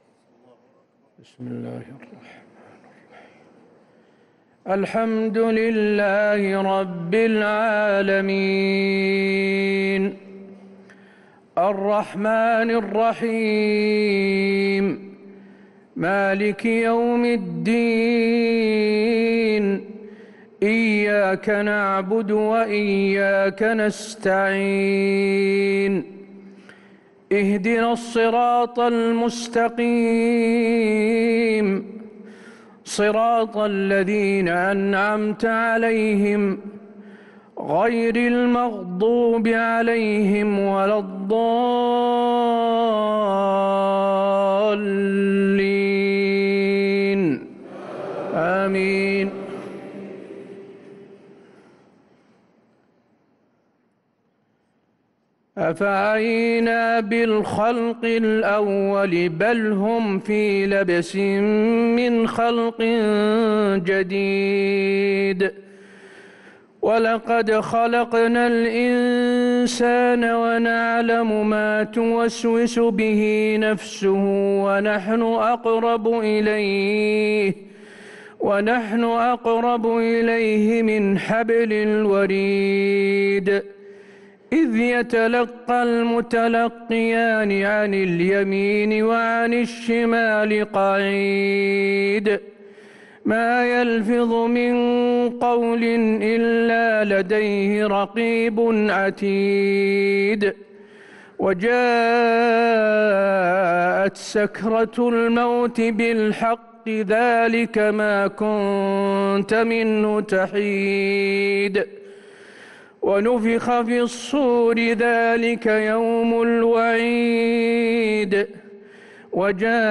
صلاة العشاء للقارئ حسين آل الشيخ 3 ذو الحجة 1444 هـ
تِلَاوَات الْحَرَمَيْن .